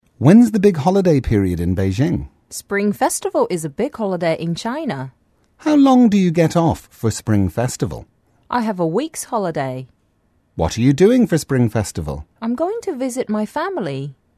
english_14_dialogue_1.mp3